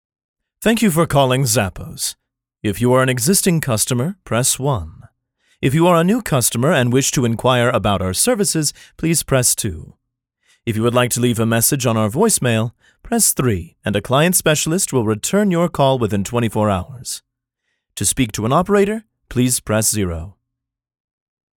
Classically Trained actor who specializes in Narration, commercial, video game, and animation voice over.
Sprechprobe: Werbung (Muttersprache):
IVR Script.mp3